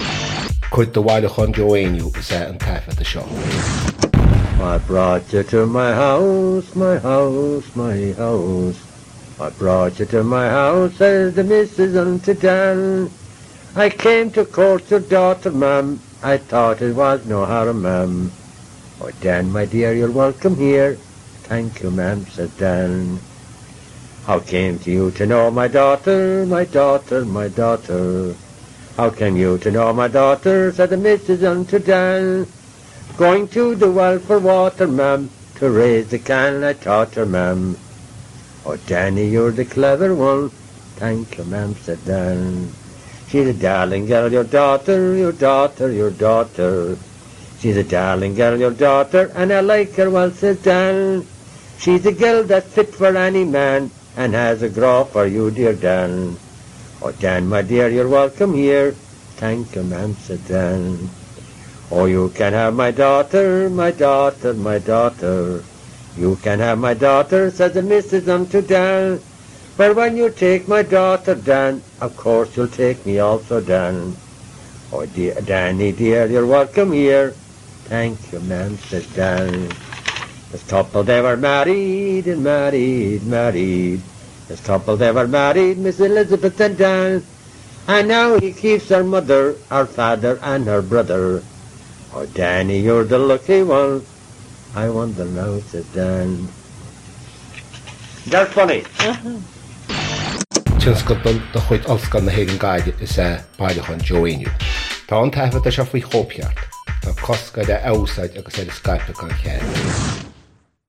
• Catagóir (Category): song.
• Ainm an té a thug (Name of Informant): Joe Heaney.
• Suíomh an taifeadta (Recording Location): University of Washington, United States of America.
Note the sound of page-turning between the last two verses. It seems likely that Joe sang this from a printed source.